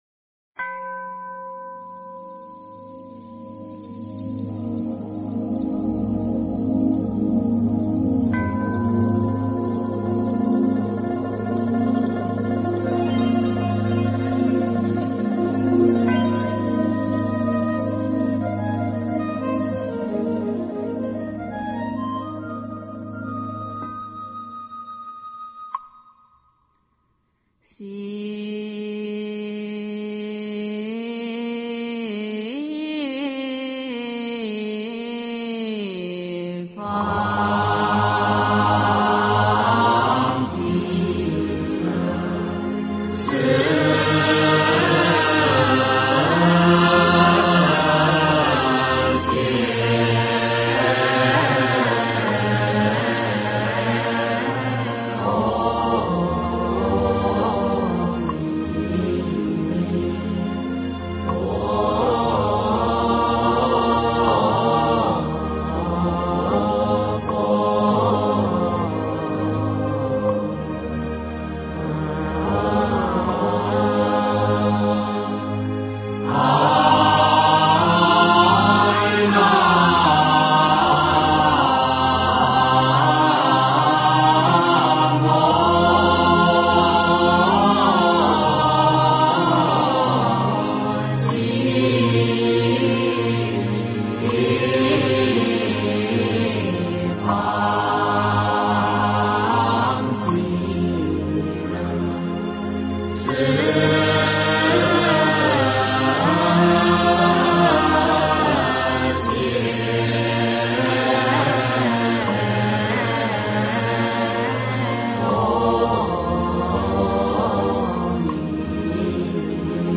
弥陀圣号千华调--佛光山 经忏 弥陀圣号千华调--佛光山 点我： 标签: 佛音 经忏 佛教音乐 返回列表 上一篇： 早课--辽宁海城大悲寺 下一篇： 楞严咒 大悲咒 十小咒--中台禅寺 相关文章 药师灌顶真言--圆光佛学院法师 药师灌顶真言--圆光佛学院法师...